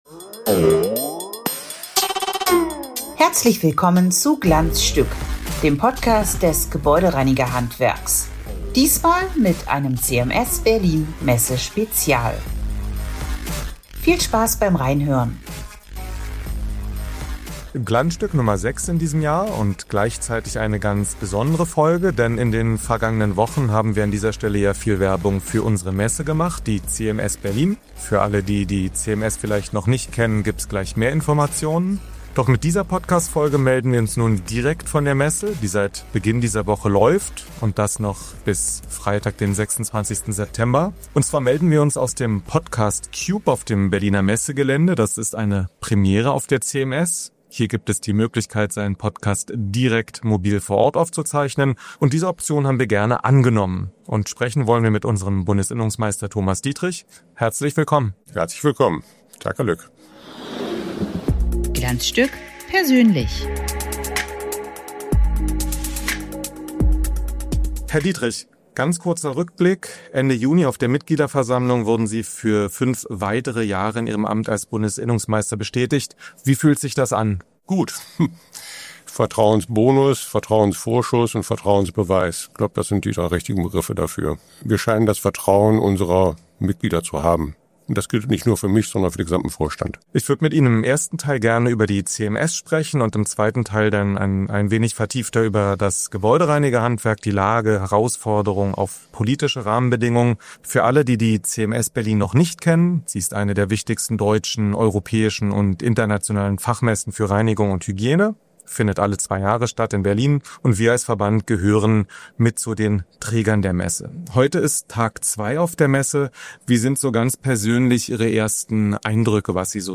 Und das mit einer kommunikativen Premiere: Das Interview ist eigens im neuen CMS-Podcast-Cube in Halle 5.2. aufgezeichnet worden.